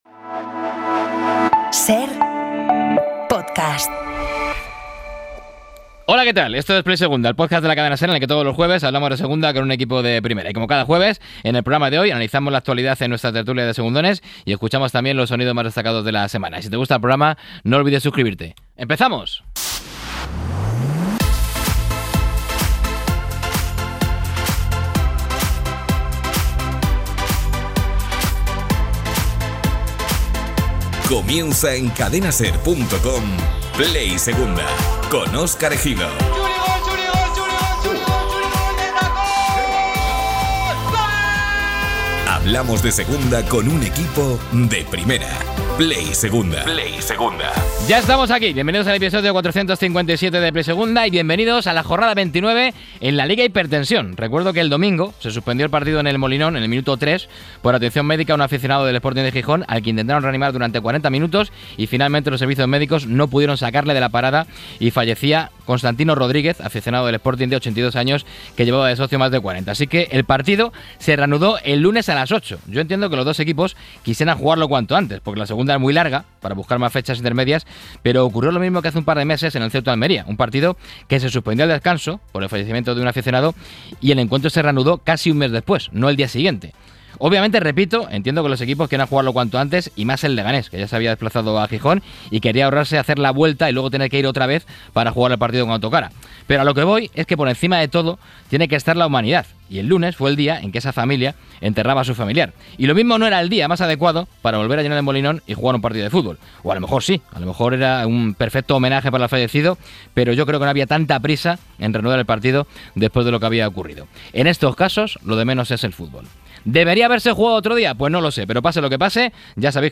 Hacemos la Tertulia de Segundones y la mejor previa de la jornada.